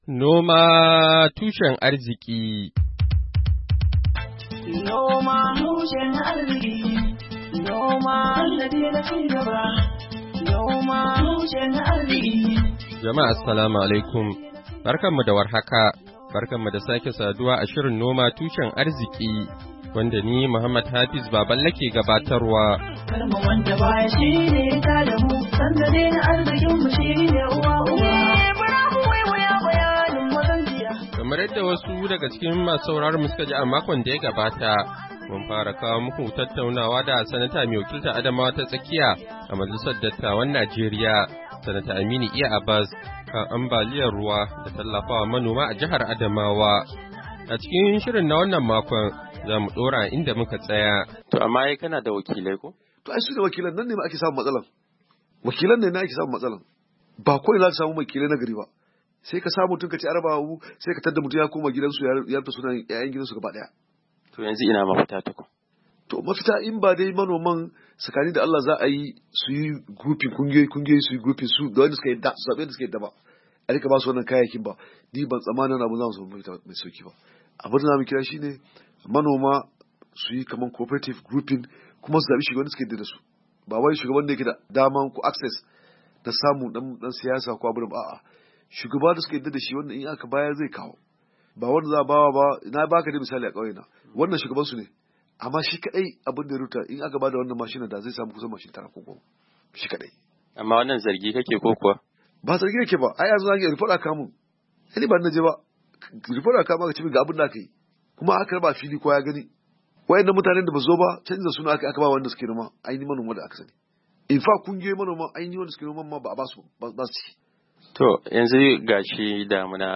Shirin Noma Tushen Arziki na wannan makon, zai kawo muku kashi na biyu na tattaunawa da Sanata mai wakiltar Adamawa ta Tsakiya a Majalisar Dattawan Najeriya, Sanata Aminu Iya Abbas, kan yadda za'a magance ambaliyar ruwa da kuma tallafawa manoma a jihar Adamawa.